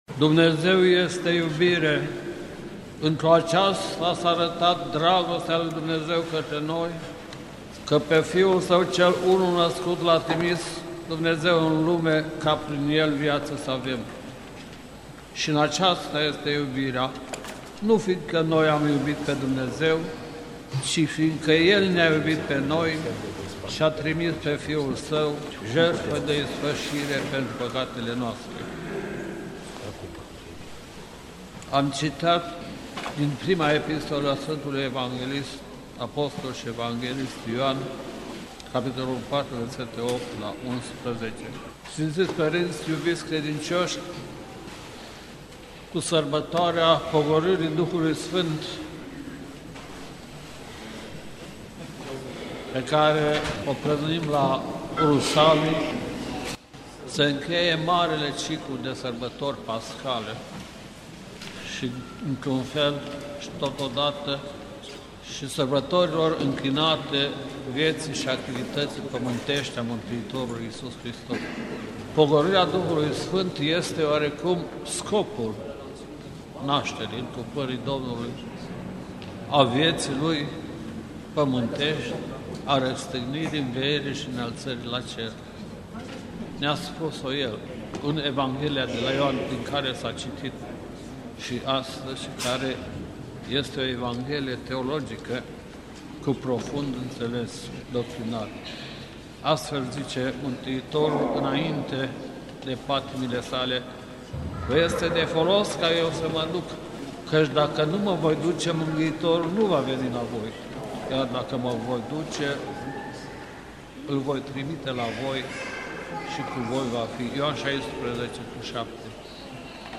Predică în A doua zi de Rusalii
rostit la Catedrala Mitropolitană din Cluj-Napoca, în A doua zi de Rusalii